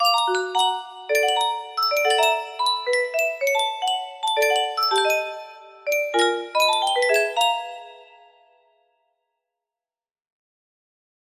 Bogy's Music box music box melody